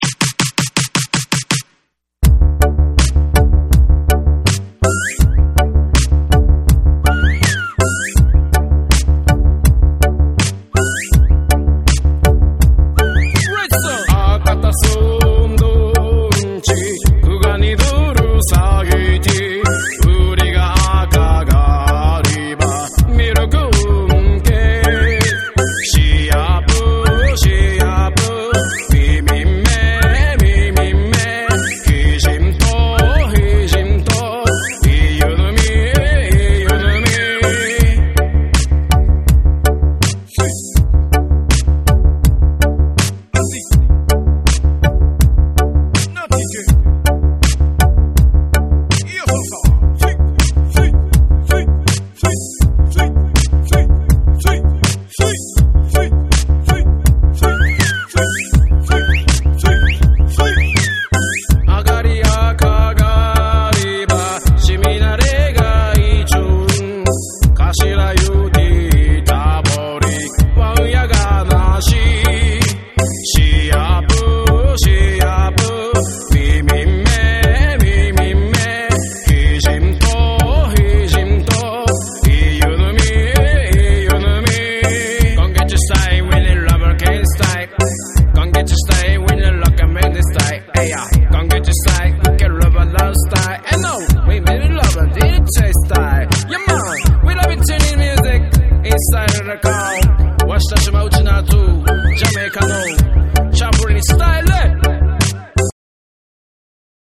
JAPANESE / REGGAE & DUB